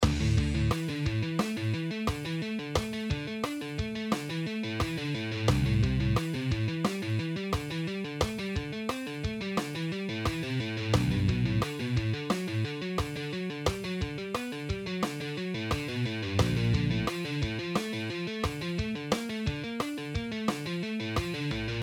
And here the lick in half speed (88BPM)